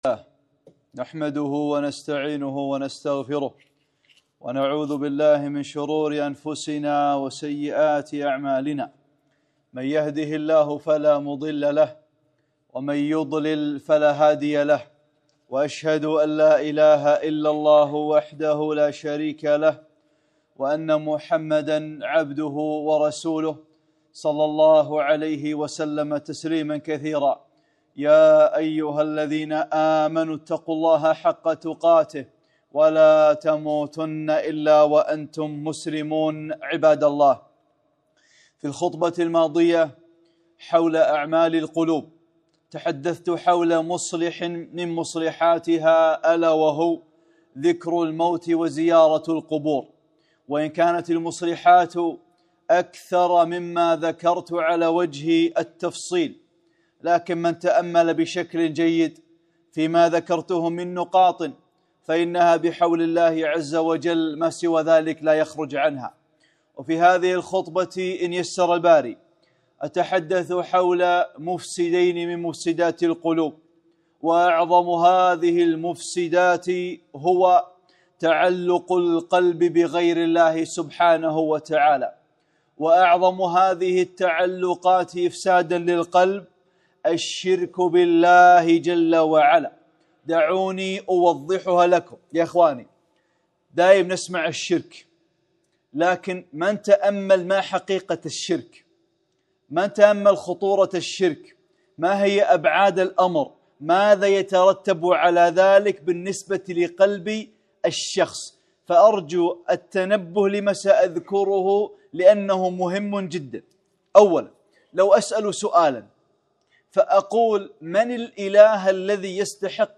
خطبة - (7) مفسدات القلوب | أعمال القلوب